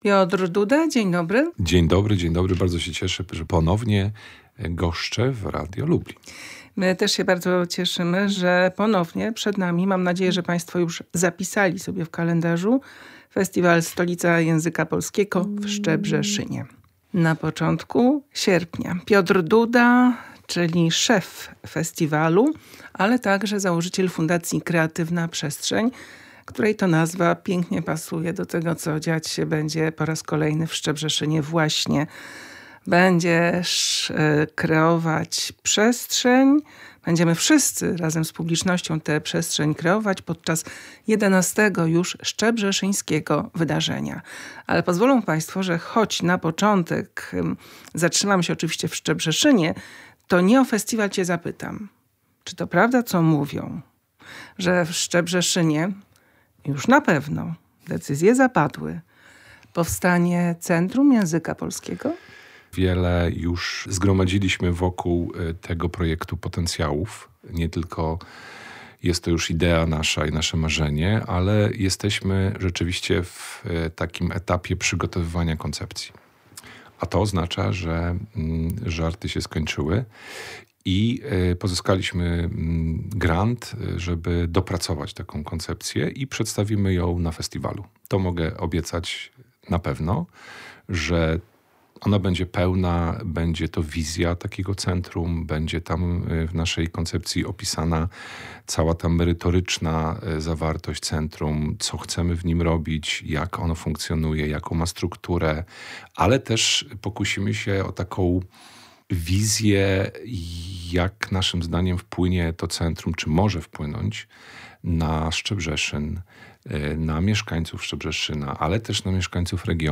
rozmawiała o niej z autorkami w Teatrze Starym w Lublinie. Wrócimy do fragmentów tego wieczoru.